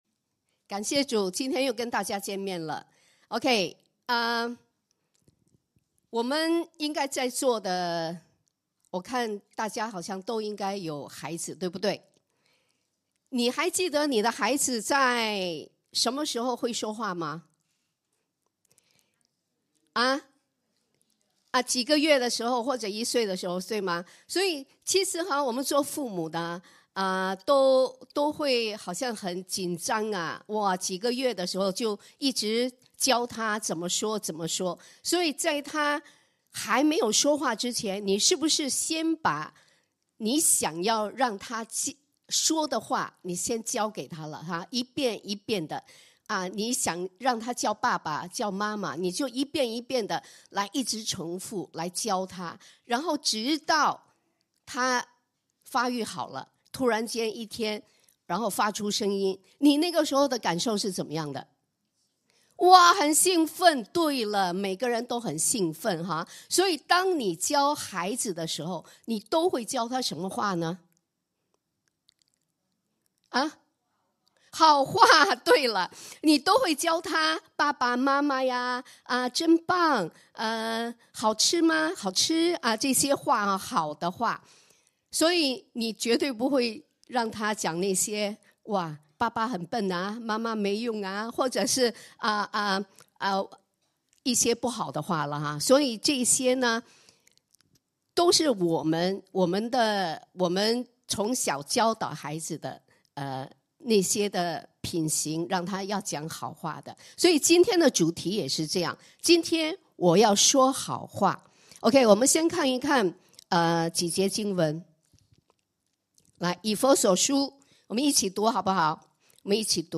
Sermon Audio (.mp3)